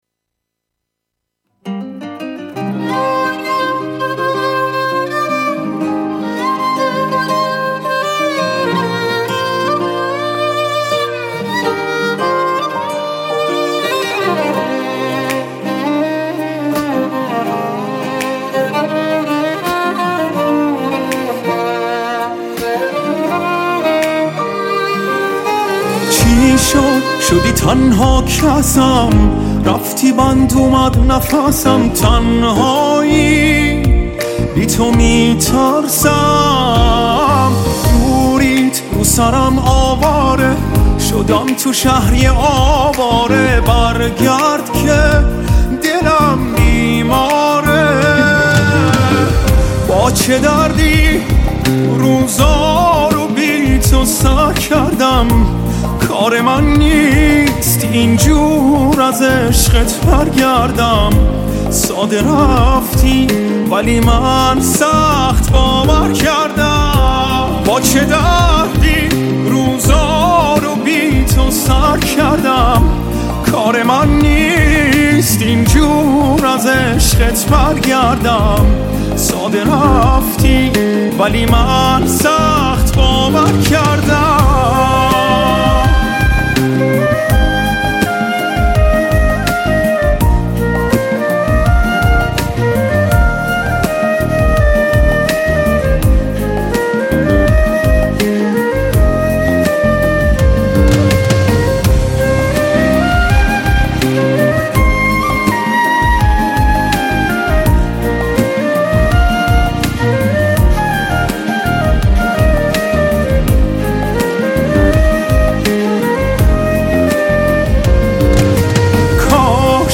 آهنگهای پاپ فارسی
کیفیت بالا